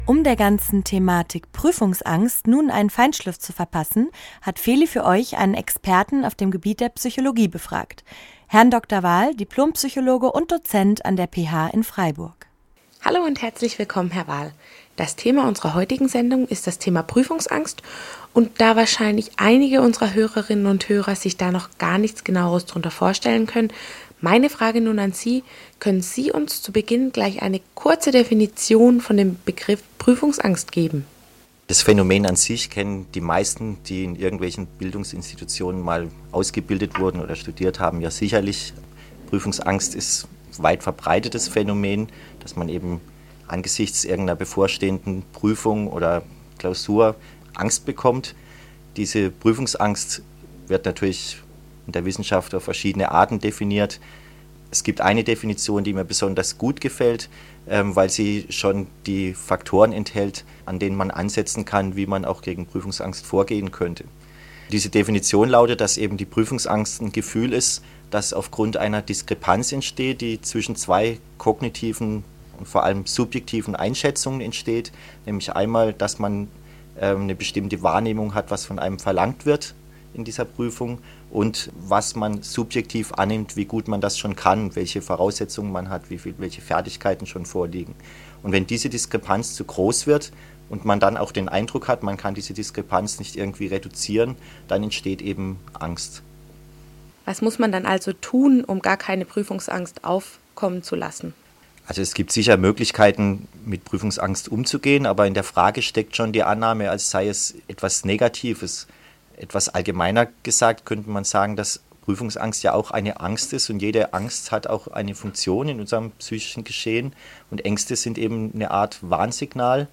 Dieser Eintrag wurde veröffentlicht unter Interview Podcast-Archiv der PH-Freiburg und verschlagwortet mit Studium deutsch am von